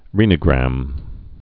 (rēnə-grăm)